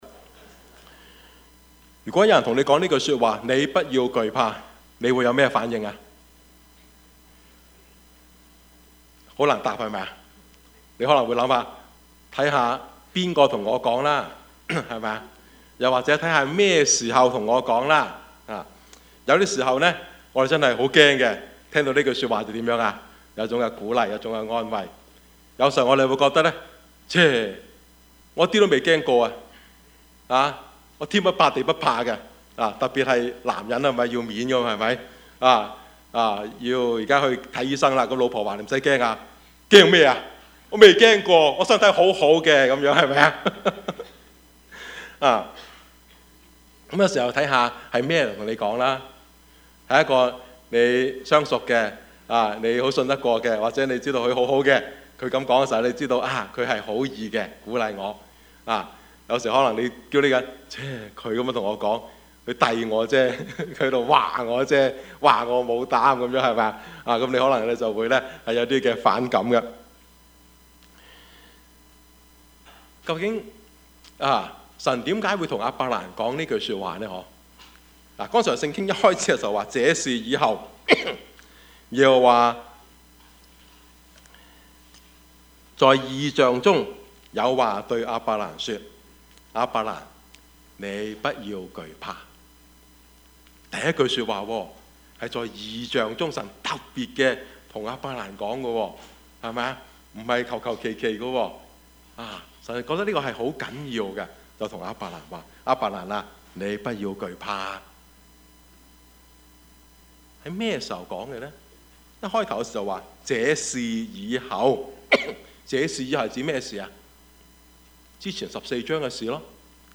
Service Type: 主日崇拜
Topics: 主日證道 « 何來平安 溝通的藝術 »